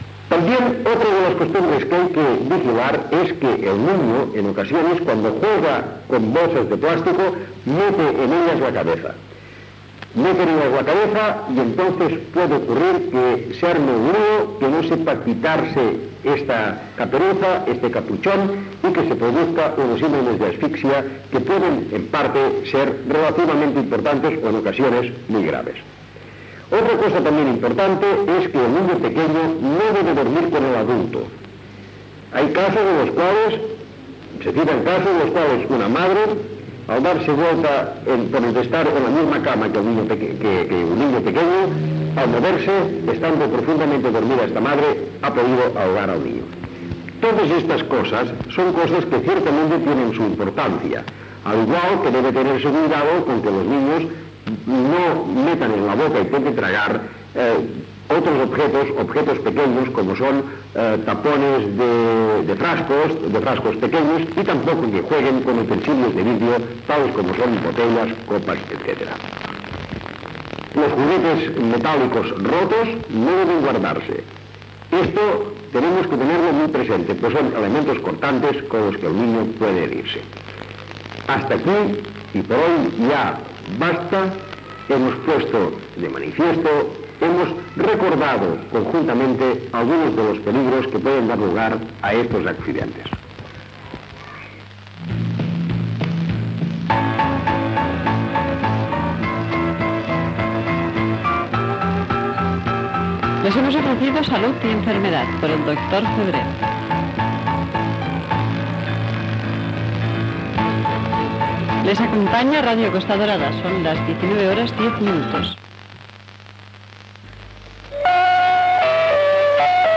Divulgació
Informatiu
FM